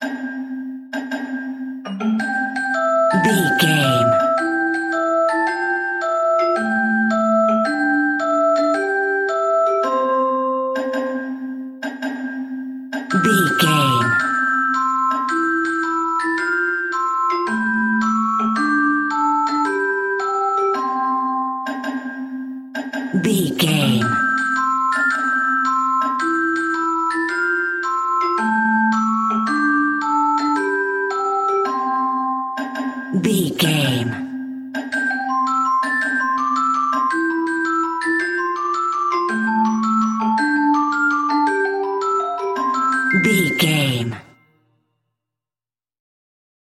Uplifting
Ionian/Major
nursery rhymes
kids music